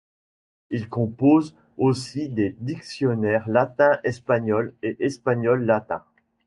Pronounced as (IPA) /kɔ̃.poz/